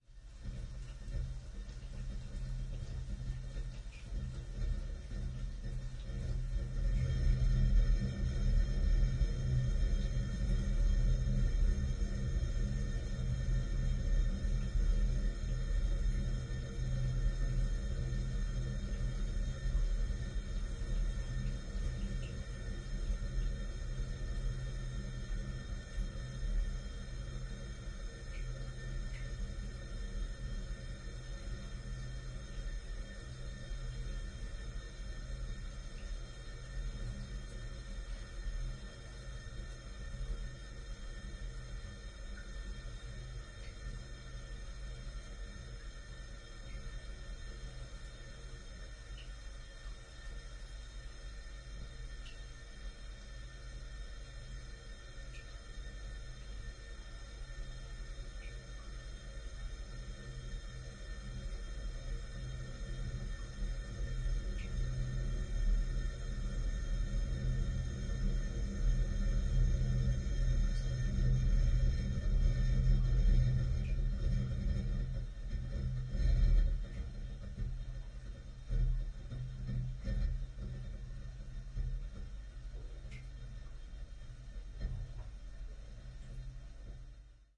描述：据我所知，这是这些水管的最后一次录音。用索尼M10在浴室和厨房的橱柜里录制的。
标签： 最小 管道 环境 无人驾驶飞机 压力 齐平 嘶嘶声 管道 轰鸣 隆隆声 浴室
声道立体声